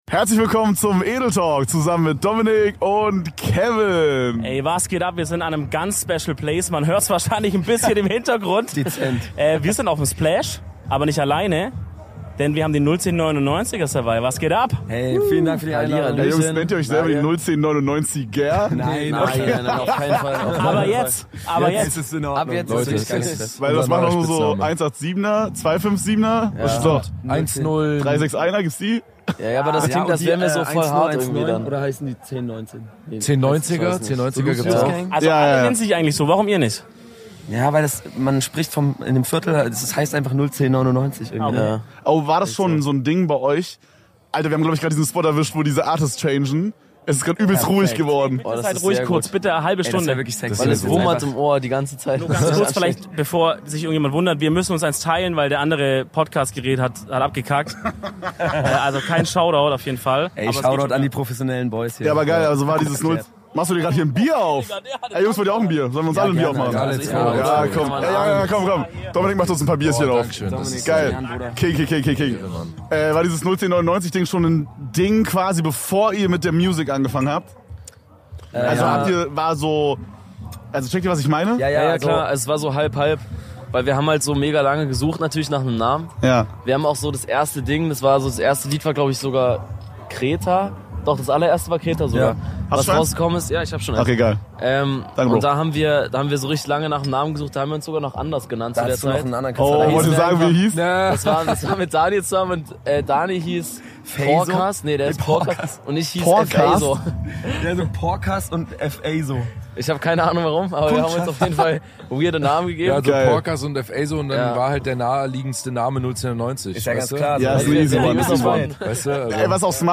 Wir haben die Jungs von 01099 zu Gast und podcasten live vom Splash, mit Kid LAROI im Hintergrund auf der Mainstage.